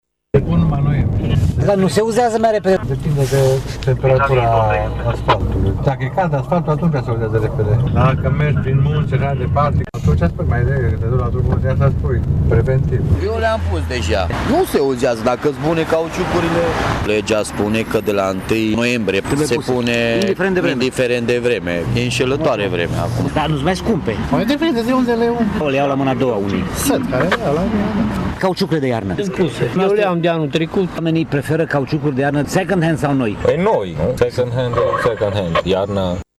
Prin urmare, o parte dintre conducătorii auto spun că preferă să li se uzeze cauciucurile mai repede, dacă nu e înghețat carosabilul, decât să fie amendați, iar cei mai mulți și-au luat cauciucuri noi, chiar dacă sunt de 8-10 ori mai scumpe: